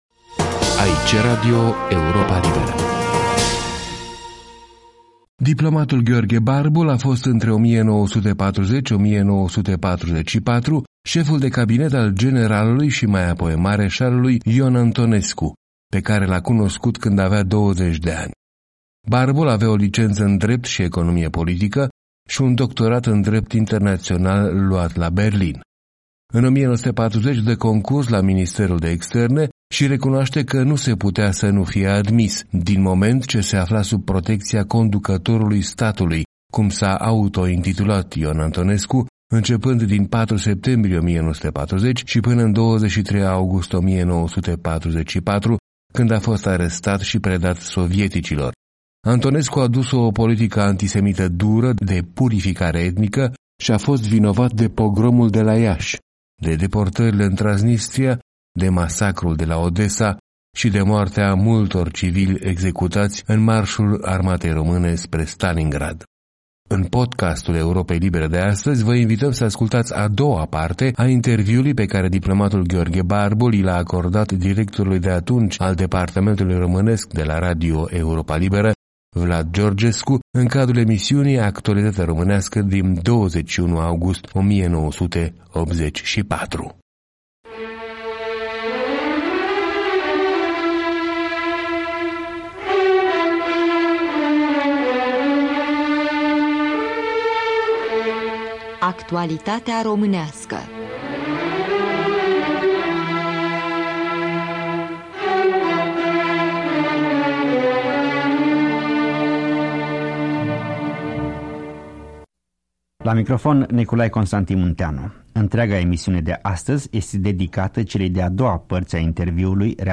A doua parte a interviului
în cadrul emisiunii „Actualitatea românească” în august 1984.